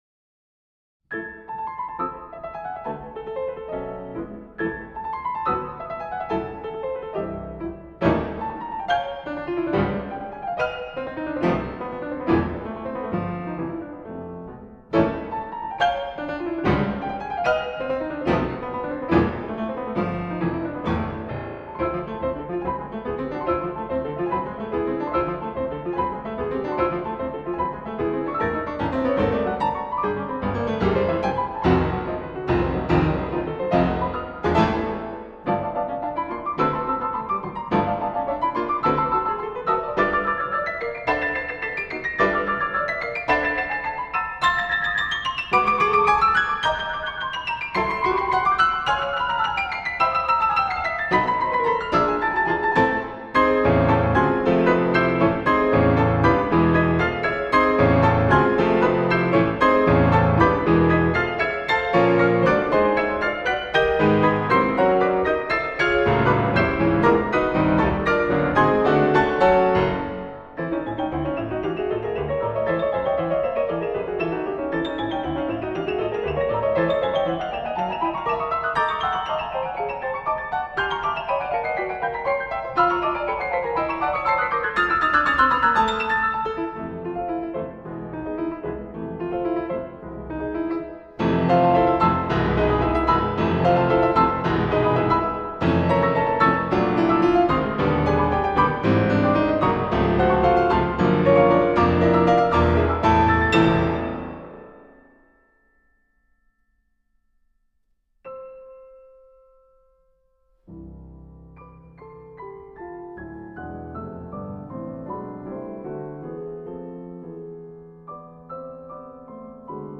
for two Pianos